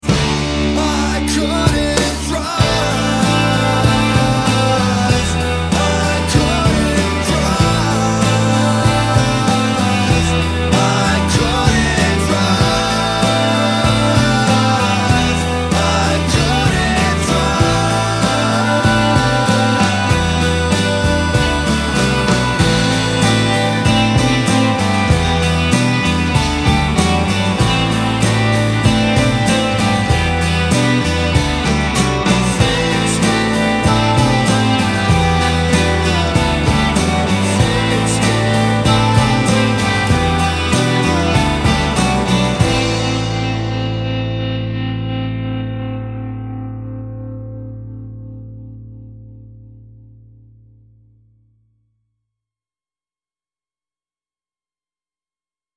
Follow this with the extro from Brand New Car, deliberately mixed to have a thick bottom end, and the contrast makes the bass in the 2nd track sound overpowering.